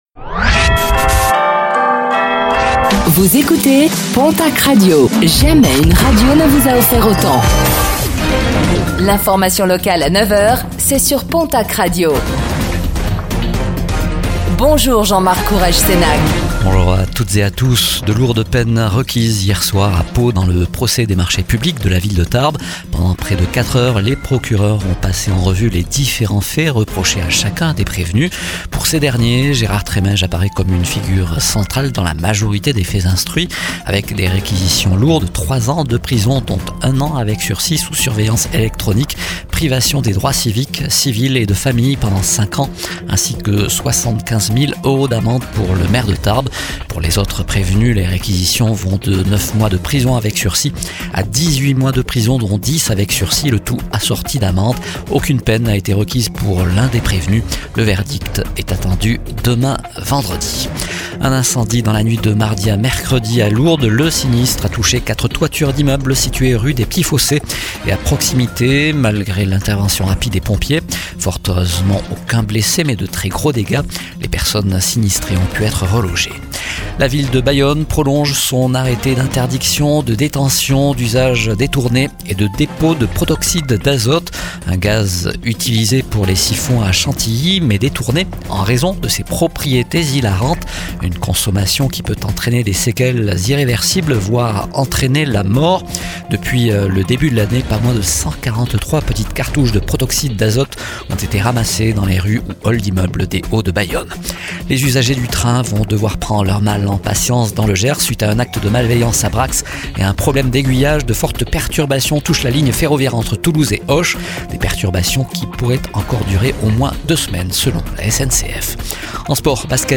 Réécoutez le flash d'information locale de ce jeudi 20 novembre 2025